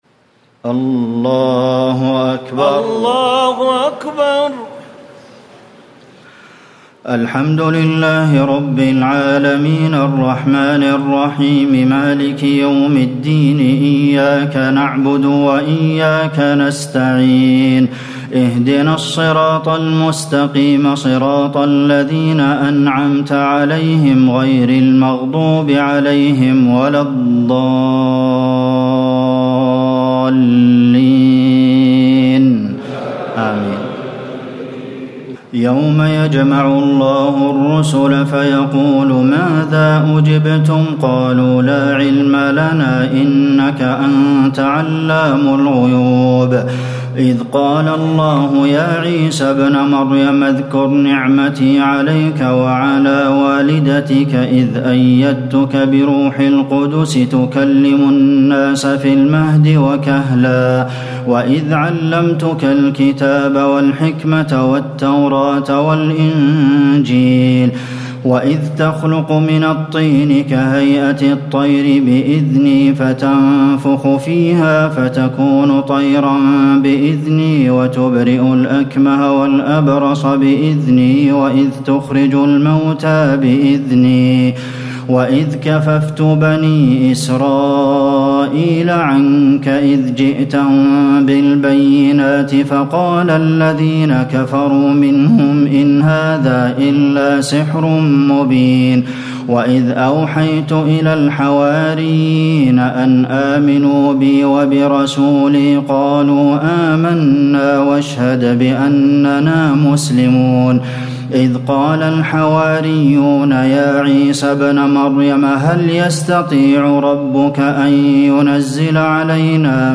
تراويح الليلة السادسة رمضان 1439هـ من سورتي المائدة (109-120) و الأنعام (1-73) Taraweeh 6 st night Ramadan 1439H from Surah AlMa'idah and Al-An’aam > تراويح الحرم النبوي عام 1439 🕌 > التراويح - تلاوات الحرمين